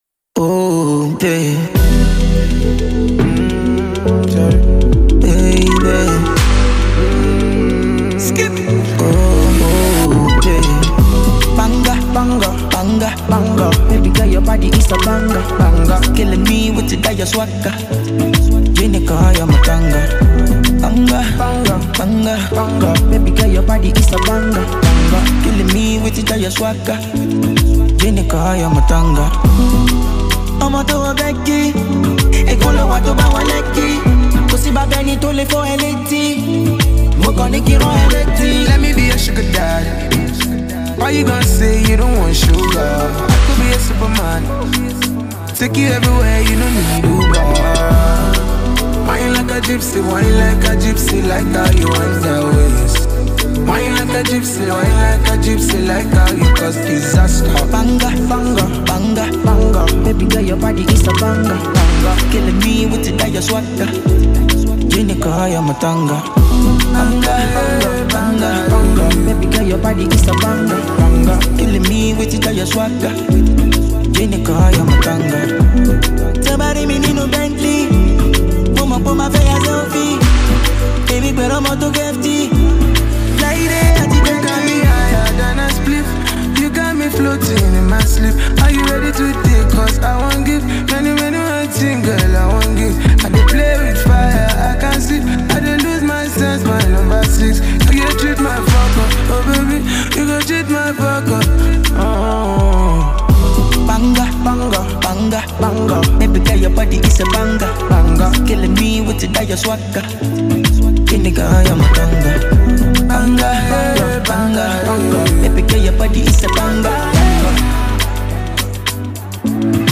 • Genre: Afrobeat